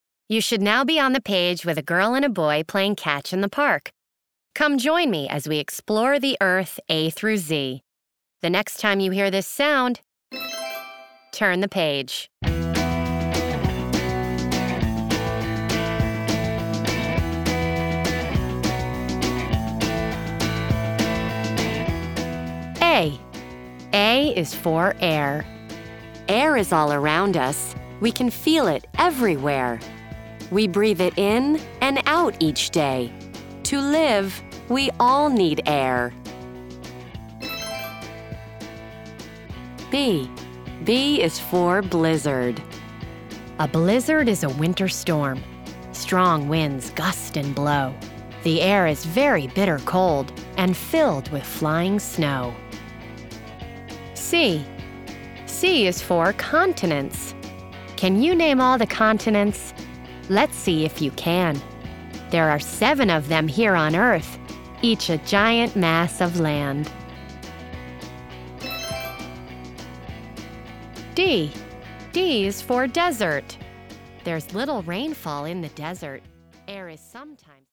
This one was for a young children’s audio learning program. Our instruction was to cast, record, and mix in music that the Smithsonian requested.